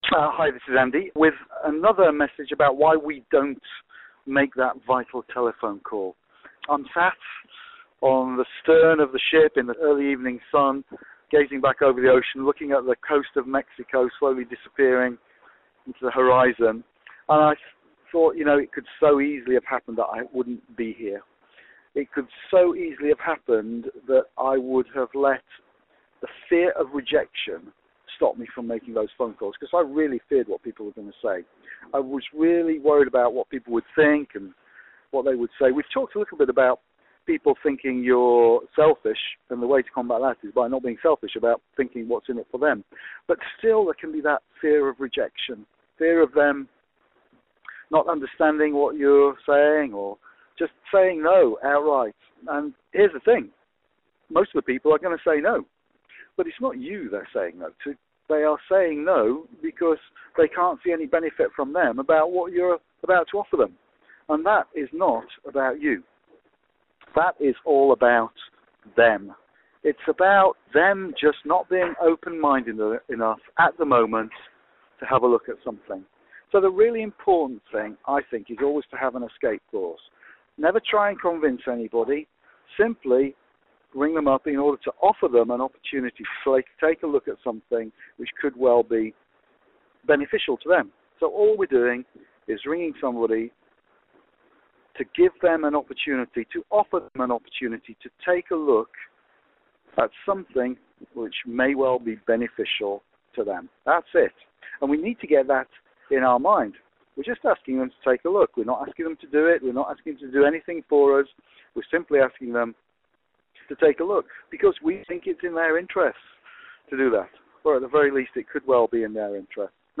I sent this from the deck of a cruise liner in the Gulf of Mexico while watching the sun go down while pondering that my success may so easily have not happened had I let my fears stop me from taking the action I needed to take.